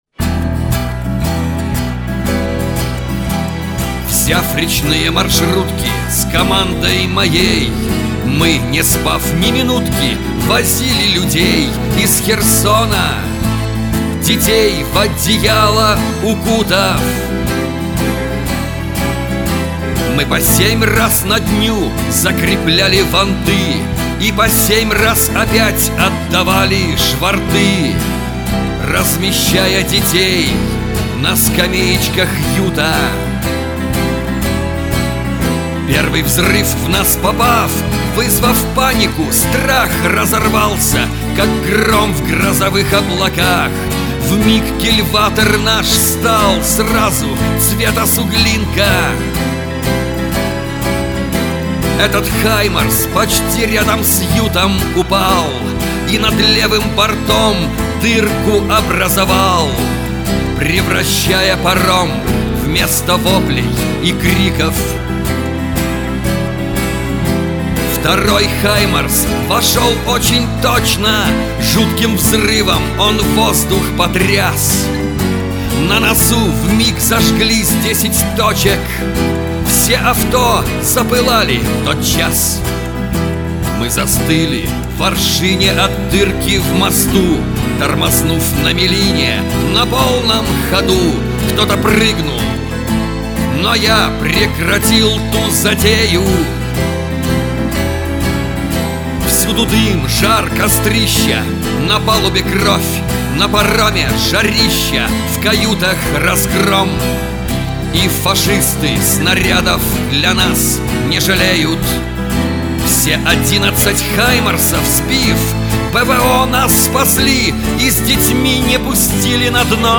Зарисовка на мотив песни В. Высоцкого «Мы взлетали как утки».